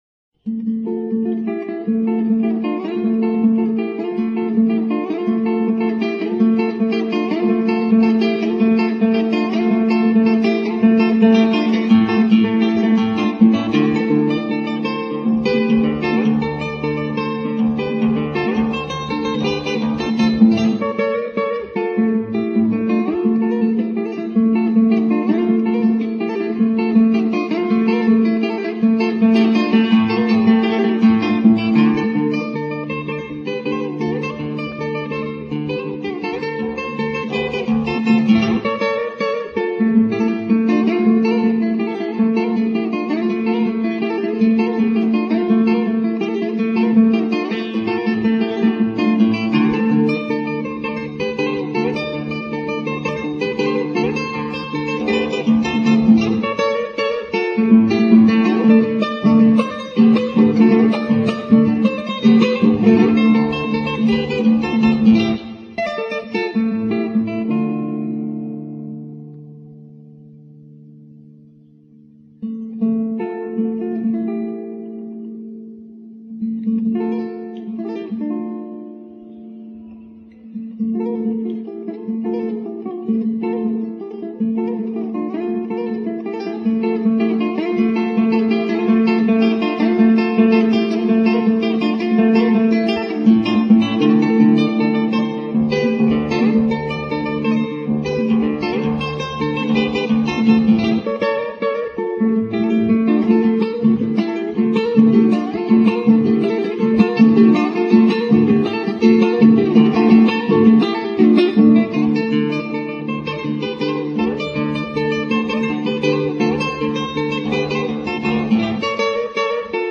arr para solista